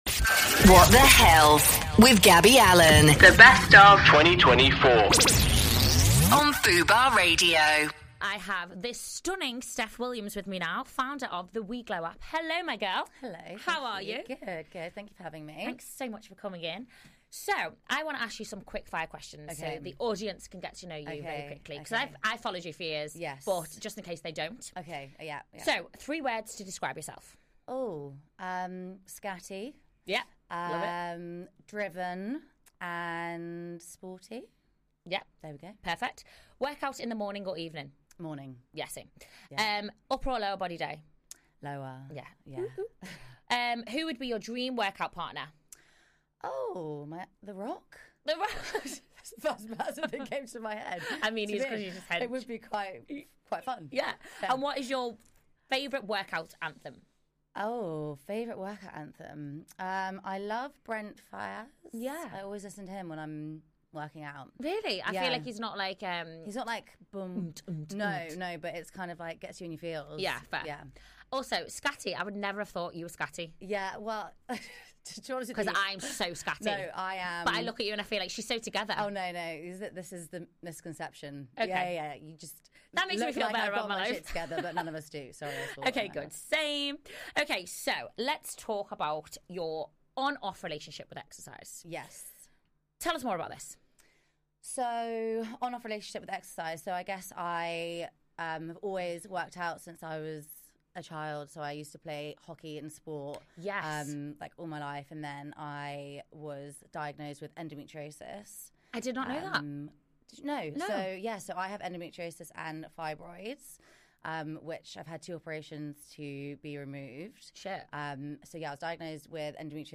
Featuring interviews